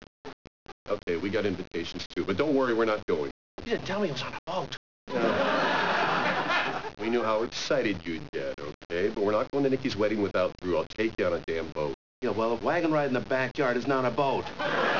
theme song - uncut